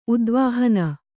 ♪ udvāhana